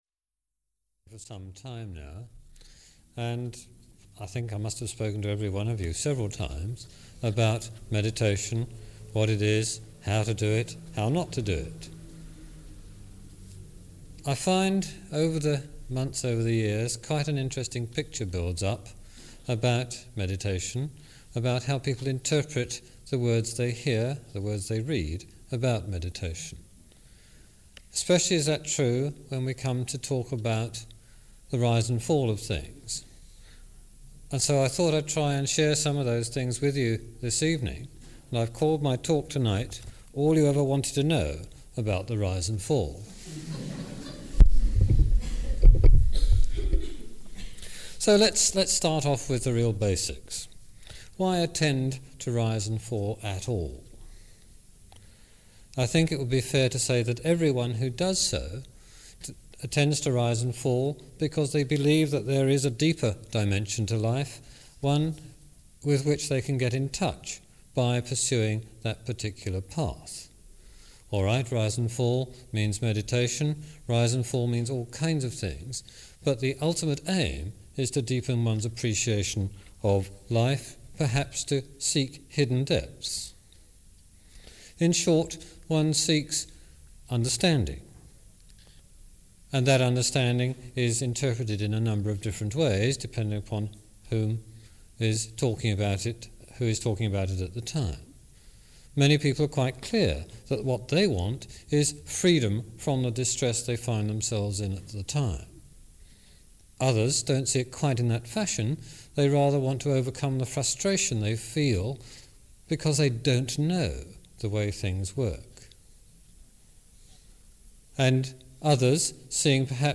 This talk was delivered in December 1988